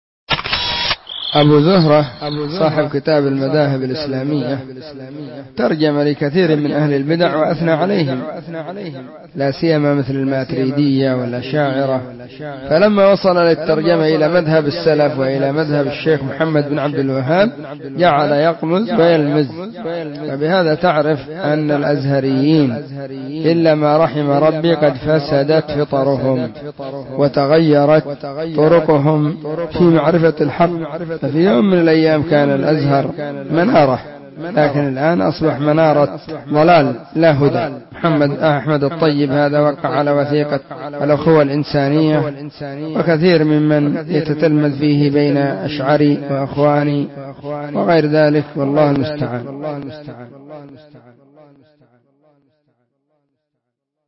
📢 مسجد الصحابة – بالغيضة – المهرة – اليمن حرسها الله.